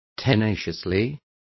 Complete with pronunciation of the translation of tenaciously.